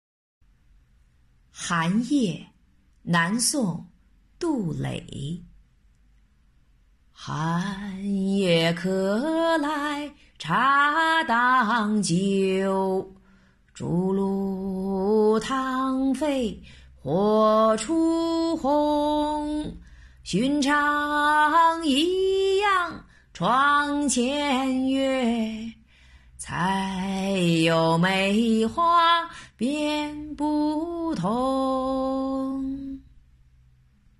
寒夜—古诗吟诵